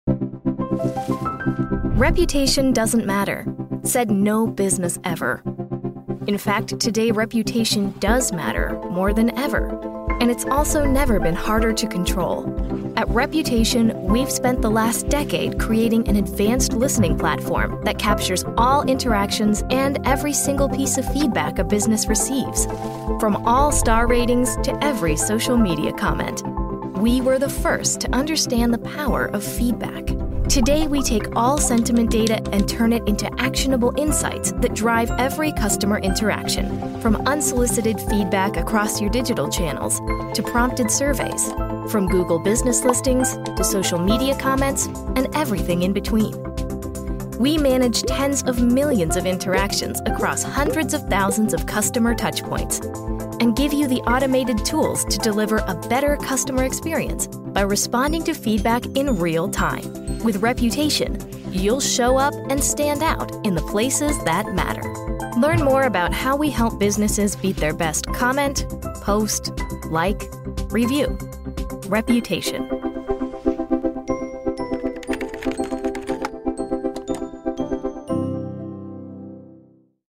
Commercieel, Natuurlijk, Vriendelijk, Warm, Zakelijk
Explainer